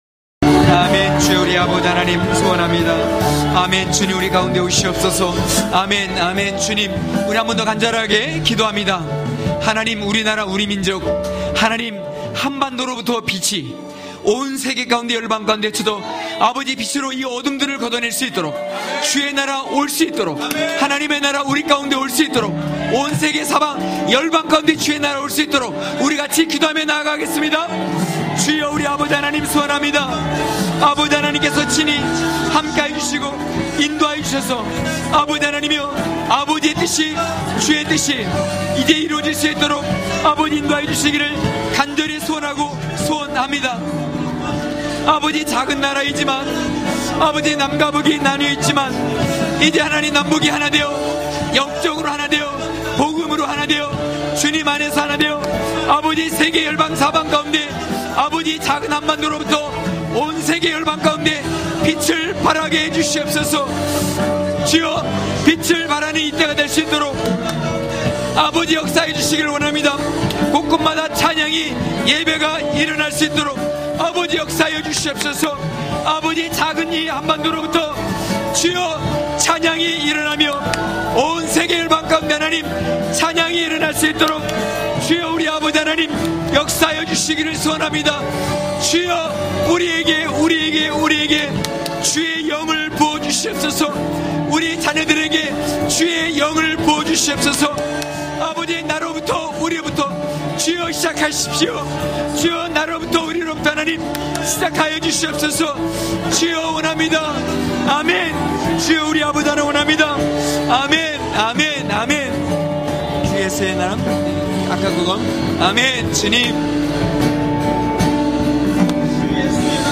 강해설교 - 12.경외함은 기업을 받게한다!!(느6장15절~7장4절).mp3